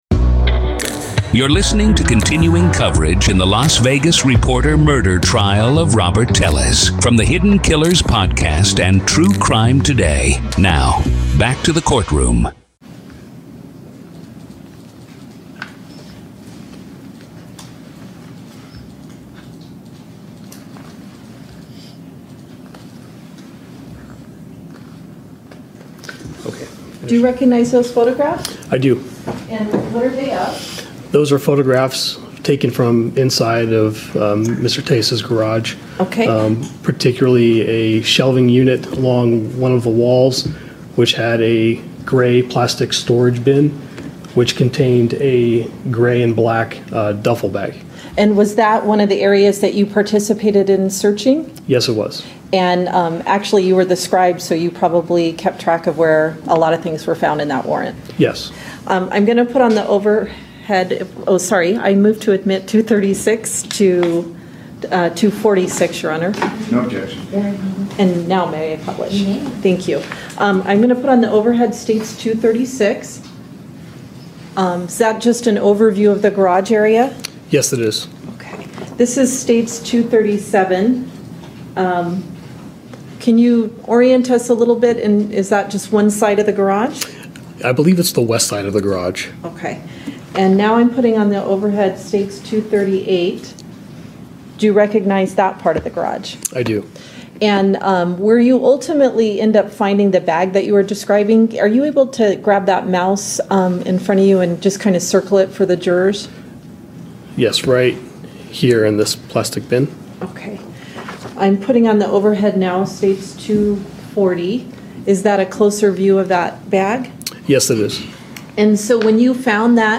Court Audio-NEVADA v. Robert Telles DAY 2 Part 4